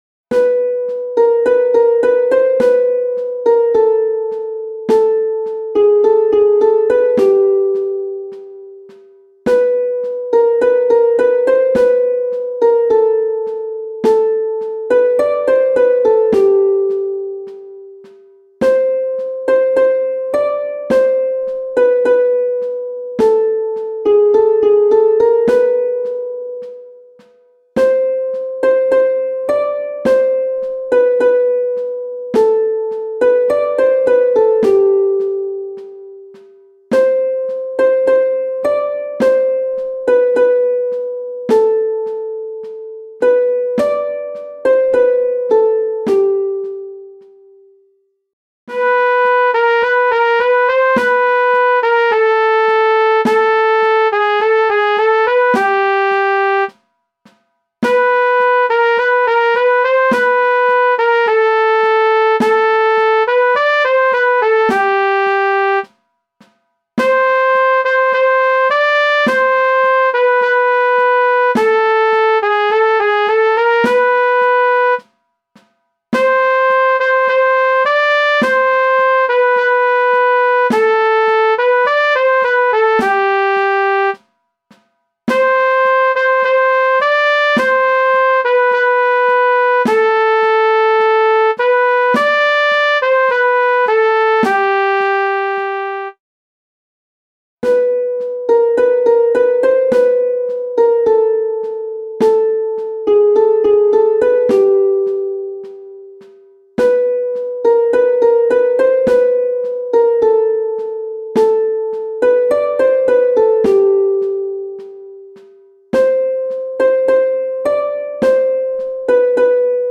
MIDI - 1-stimmig